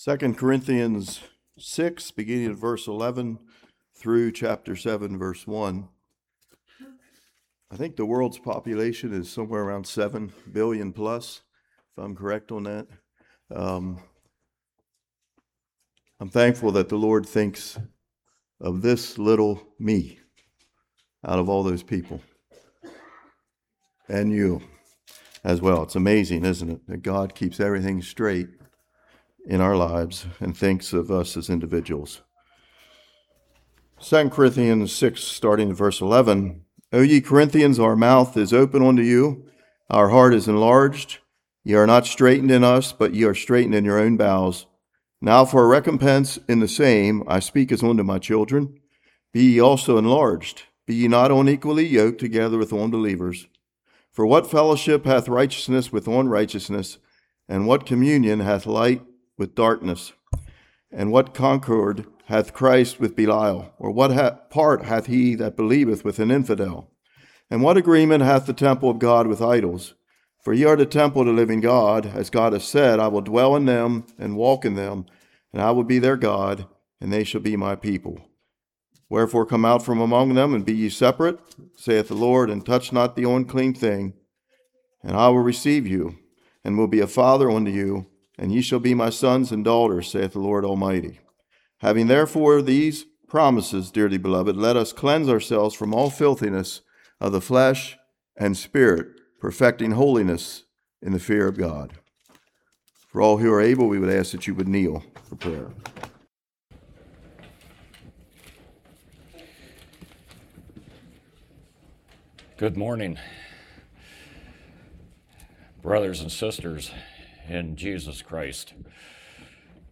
2 Corinthians 6:11-7:1 Service Type: Morning We are called to be the Salt and Light to others.